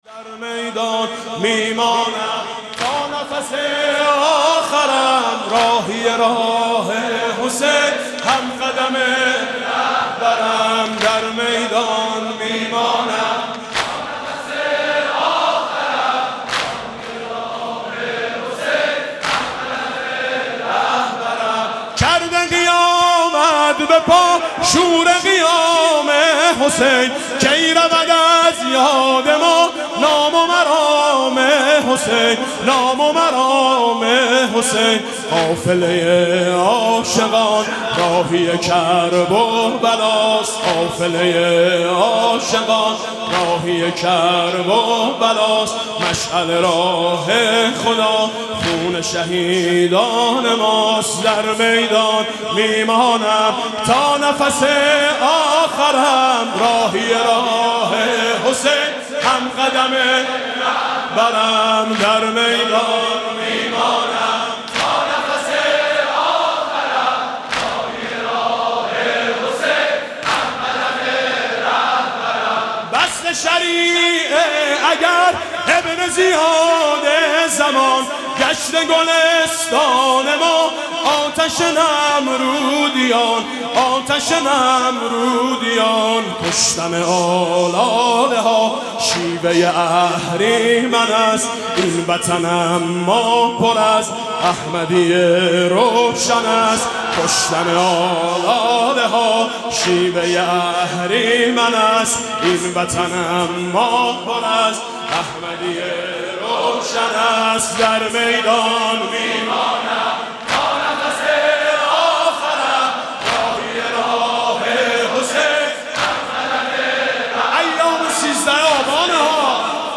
07-Shab-2-M-92.-Vahed-Tond.mp3